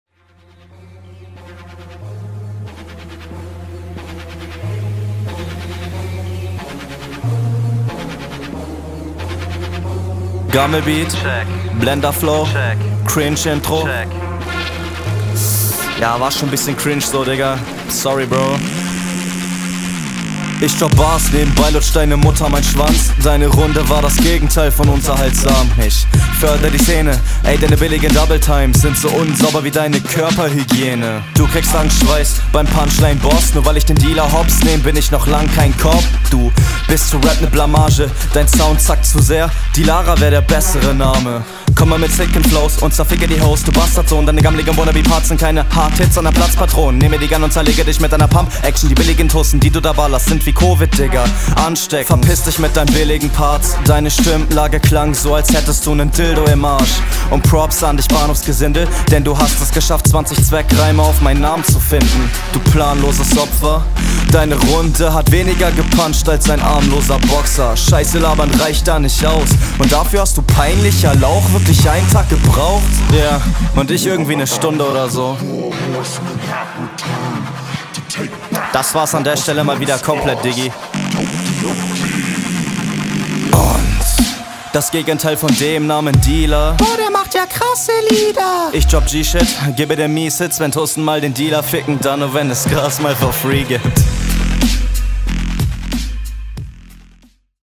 der mix ist clean af. i like that. stimmlich bist du aber viel zu dünn …
Intro abmischtechnisch schonmal gut gemacht.